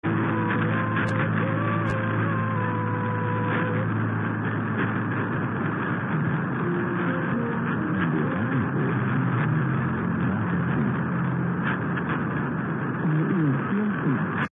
972germany.mp3